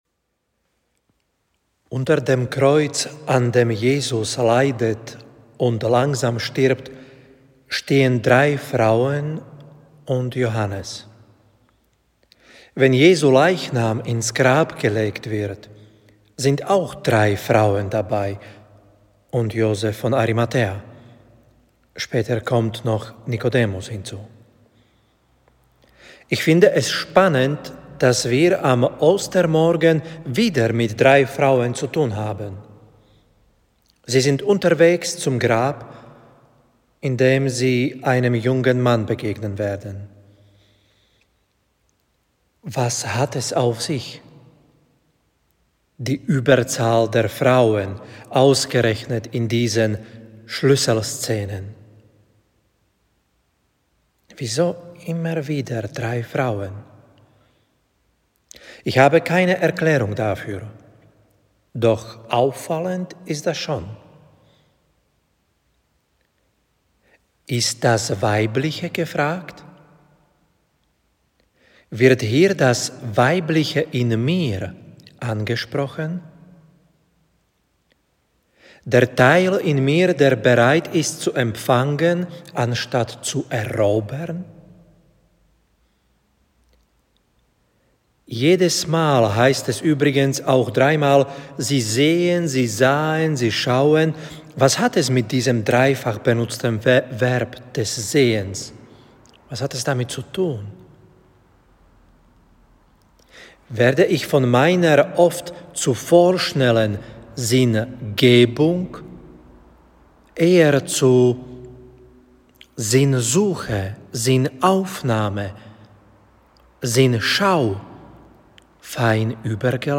Osterpredigt 2021 zu Mk 16,1-7.